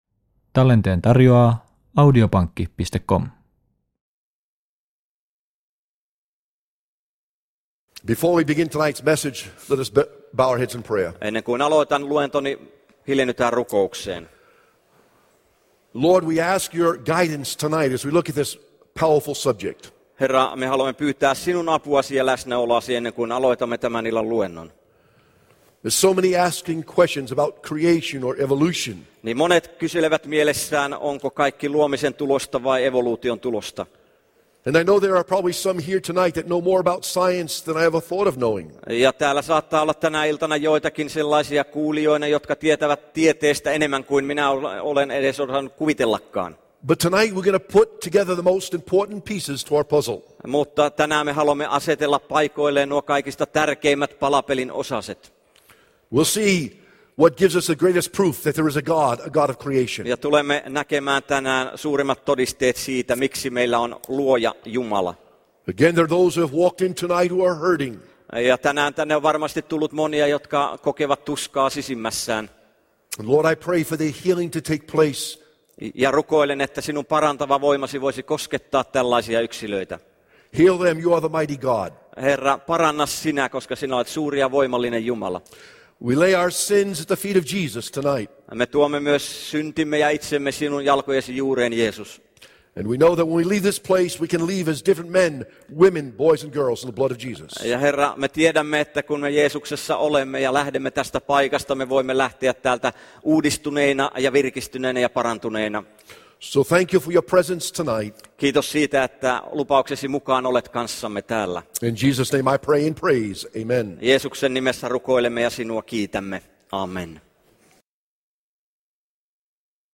Raamatussa tulevien tapahtumien ennustukset on usein puettu vertauskuvien muotoon, ja niitä on siten vaikea ymmärtää. Miksi niitä ei ole annettu selkokielellä? Kuuntelemalla tämän puhesarjan saat luotettavan ja varman tiedon Raamatun profetioista.